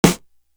Airtight Snare.wav